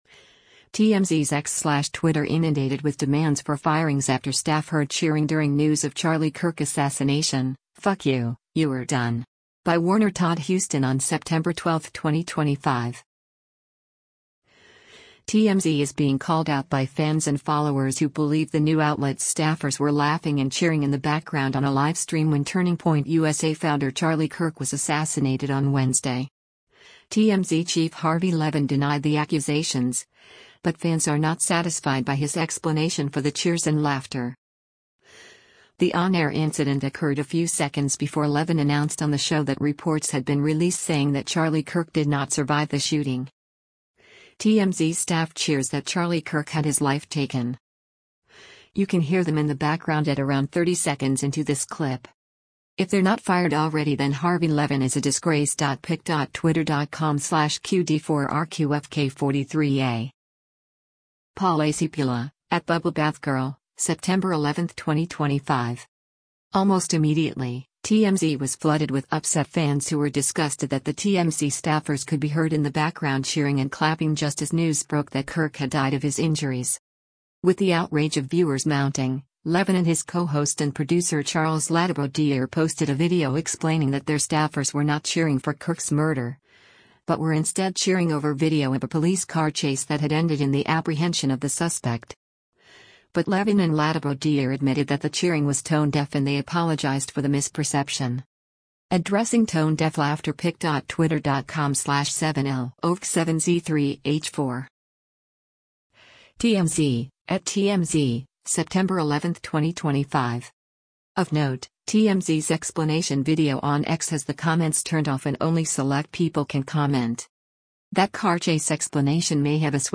Almost immediately, TMZ was flooded with upset fans who were disgusted that the TMZ staffers could be heard in the background cheering and clapping just as news broke that Kirk had died of his injuries.